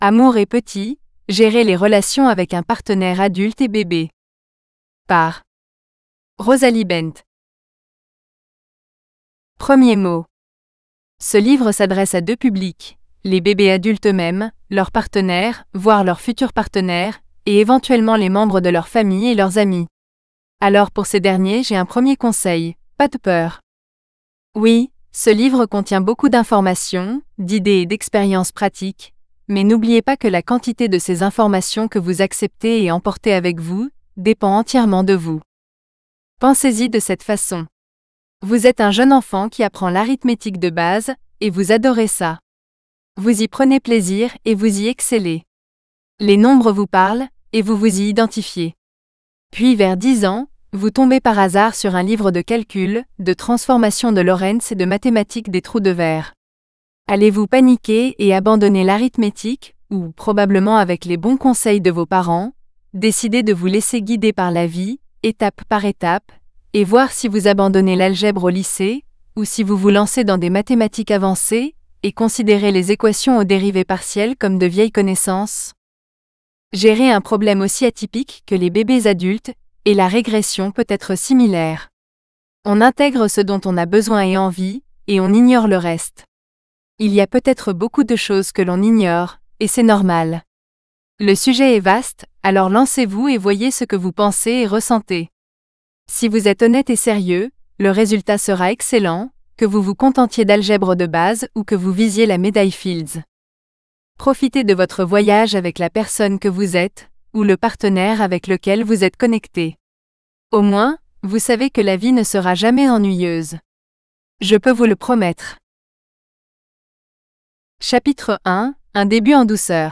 Love and Littles FRENCH (female voice AUDIOBOOK): $US6.50